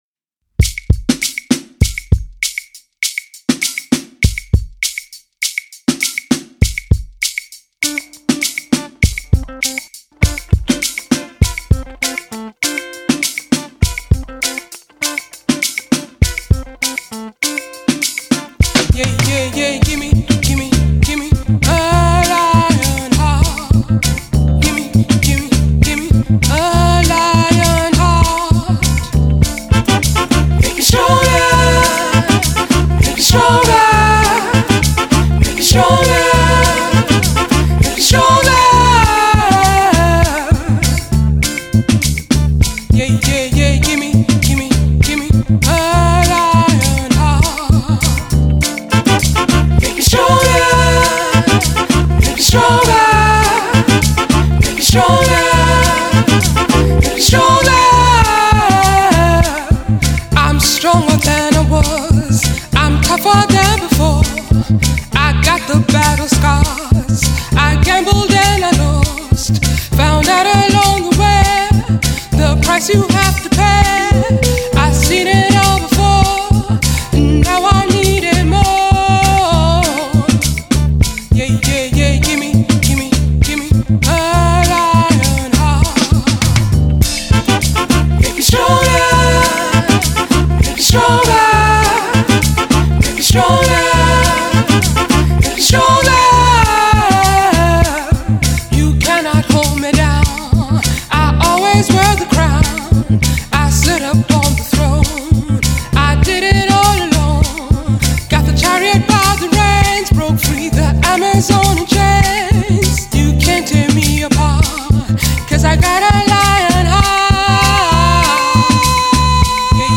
Afro-Soul